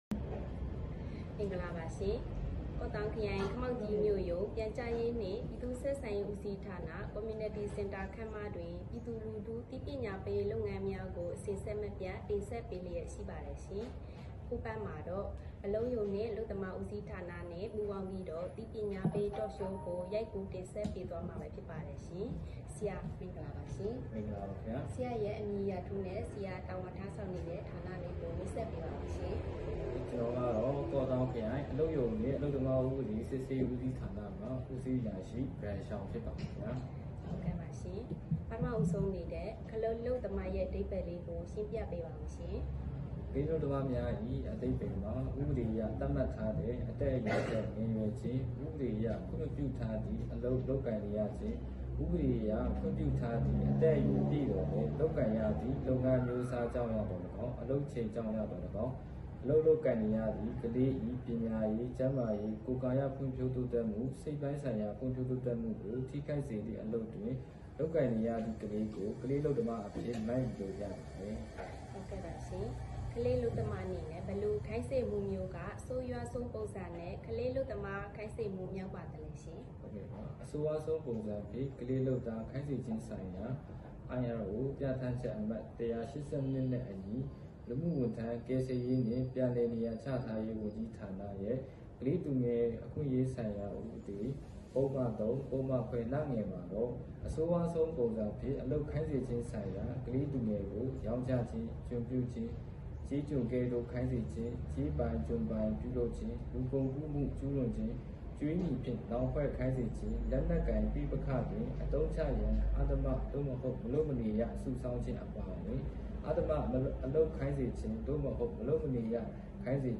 ကလေးအလုပ်သမားပပျောက်ရေးအသိပညာပေး Talk showခမောက်ကြီး၊ဖေဖော်ဝါရီ ၁၃